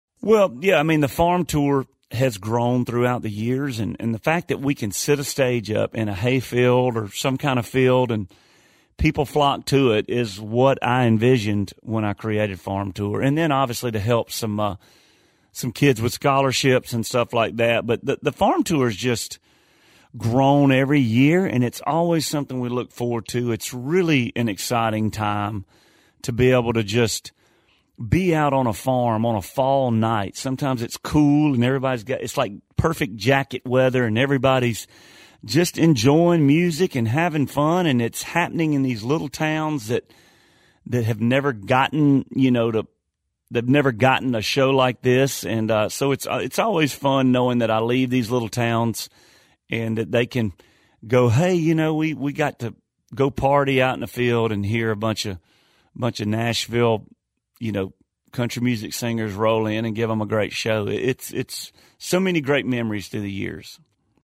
Audio / LUKE BRYAN TALKS ABOUT THE FARM TOUR AND HOW MUCH IT'S GROWN OVER THE YEARS.